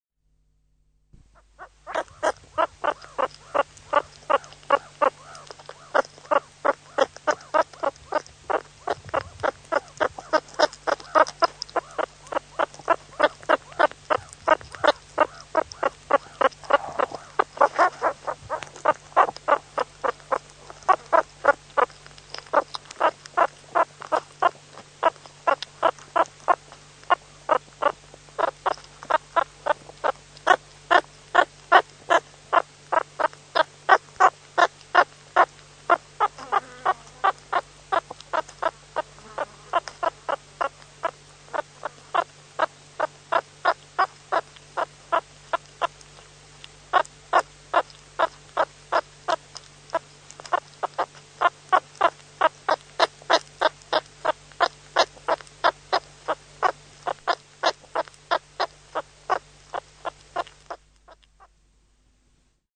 Детеныш суриката издает особый звук во время кормления мамой